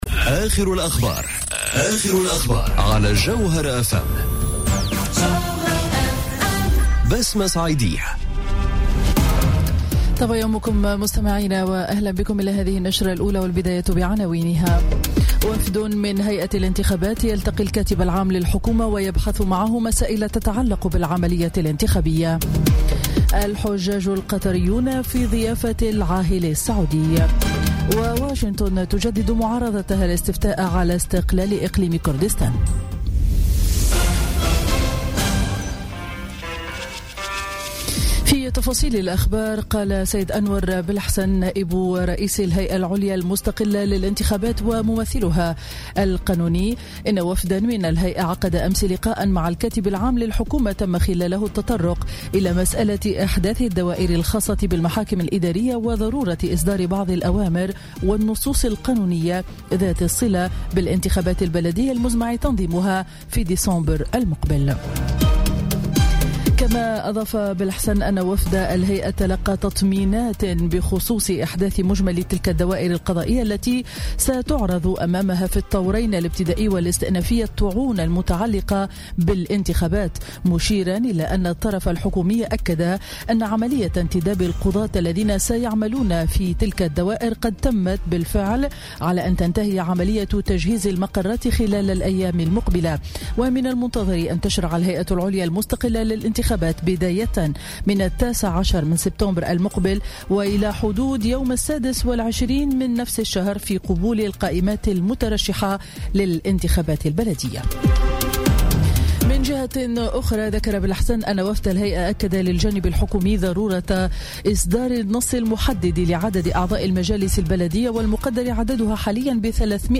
نشرة أخبار السابعة صباحا ليوم الخميس 17 أوت 2017